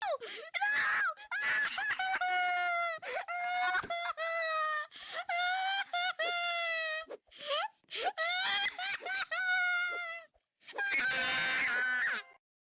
Steal A Brainrot Kid Cry Sound